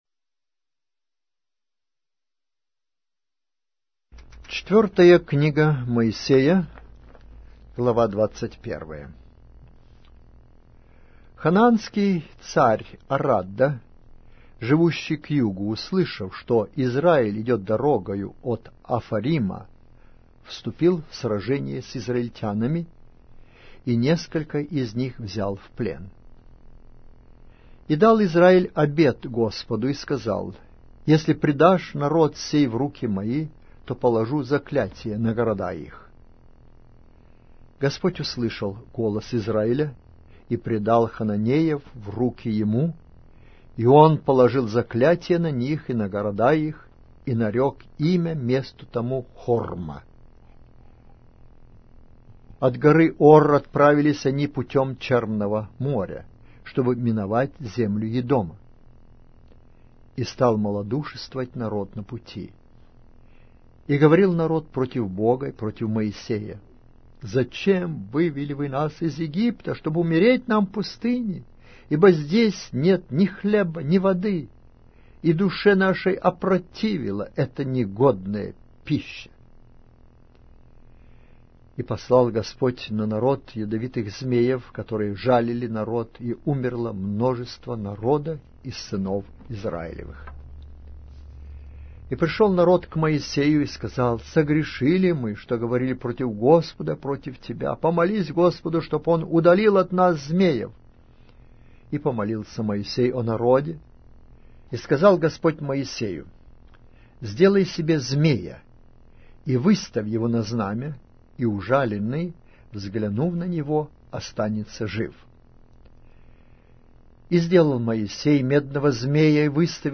Аудиокнига: Книга 4-я Моисея. Числа